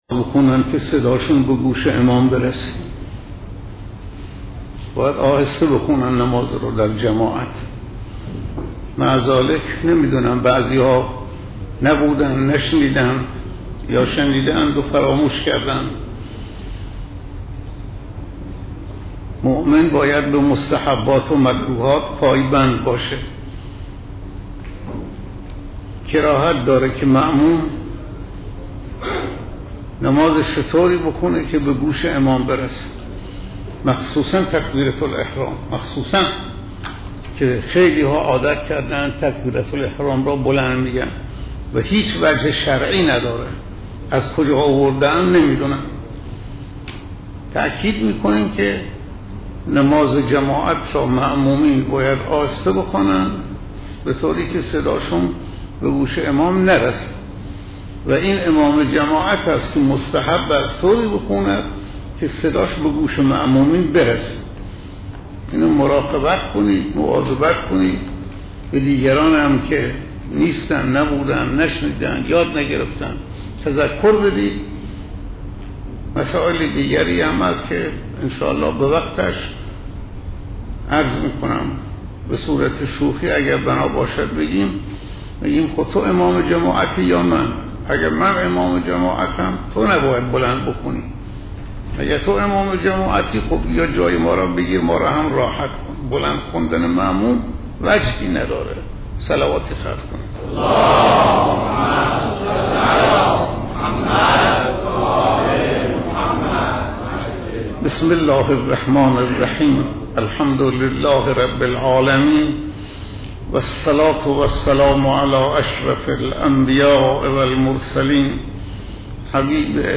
‌ سخنرانی حضرت آیت الله شفیعی روز ۱۵ ماه رمضان (پنجشنبه یازدهم تیرماه)